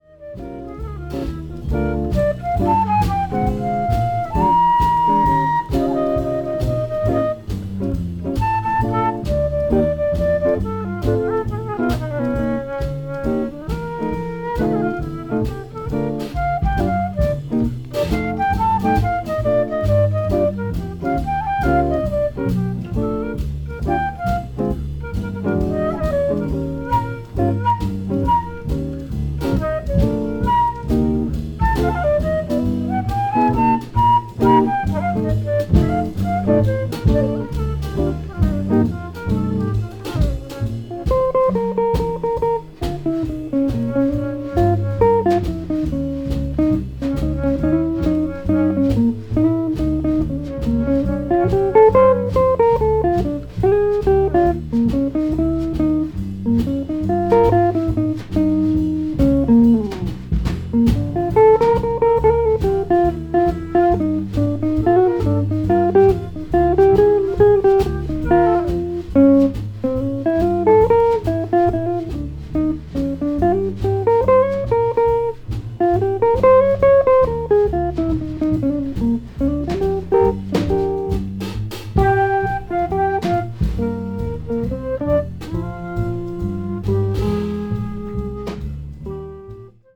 cool jazz   jazz standard   modern jazz